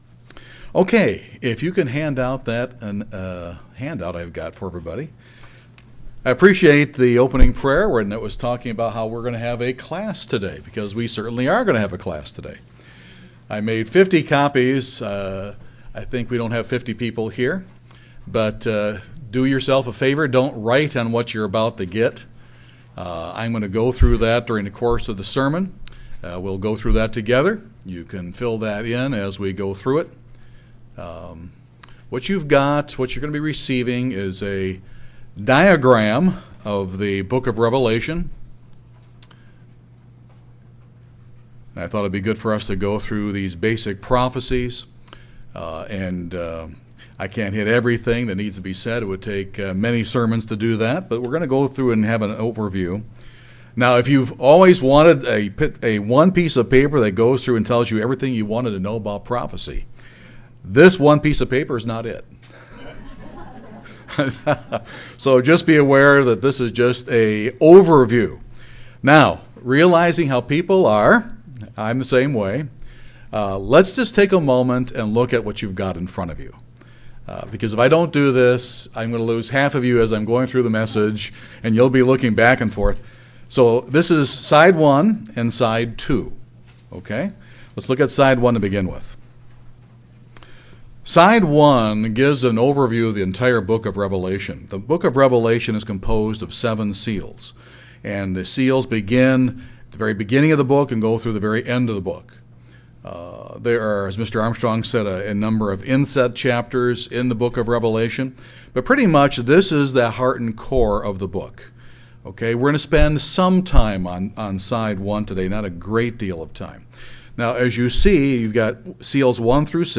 This sermon goes step-by-step through the 7 Trumpets of Revelation.